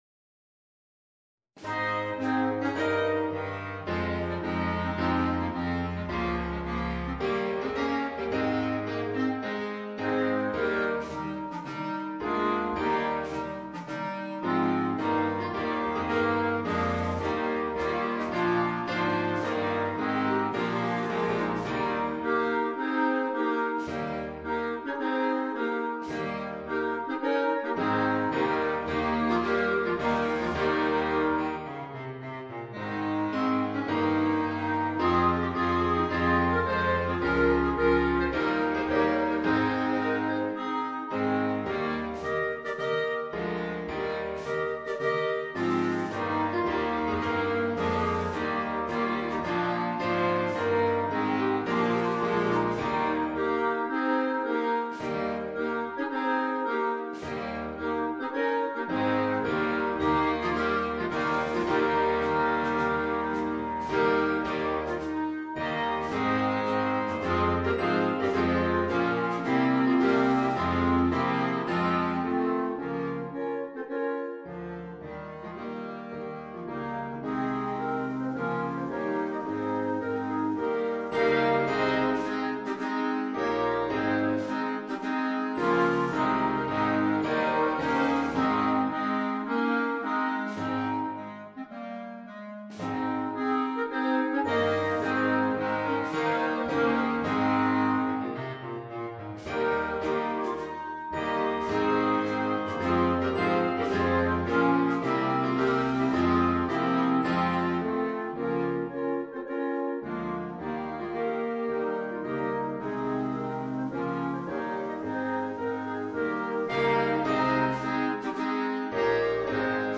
Voicing: 8 Clarinets